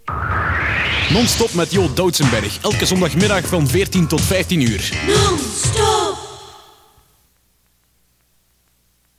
Here are some jingles.